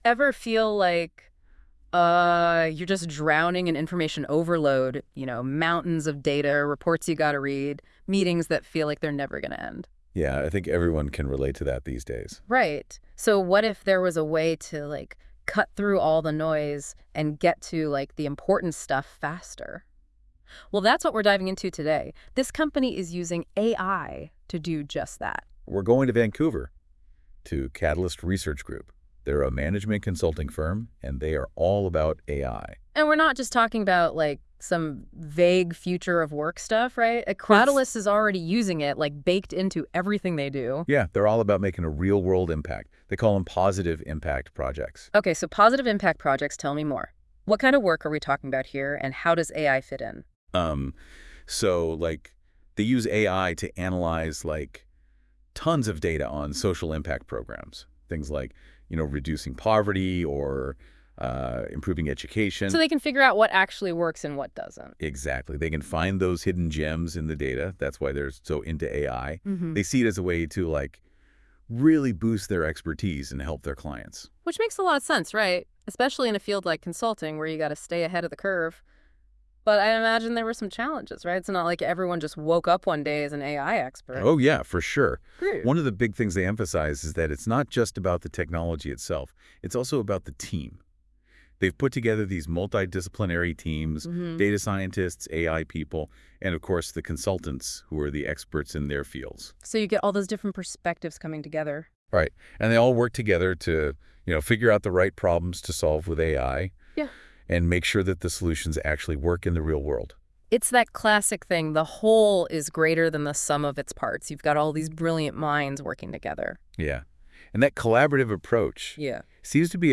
When we passed our CES BC presentation through NotebookLM, it generated a podcast of two AI-generated hosts discussing how Qatalyst is implementing AI in our processes. The result was a lively, human-sounding conversation about how AI can transform data analysis, featuring some surprising and insightful points.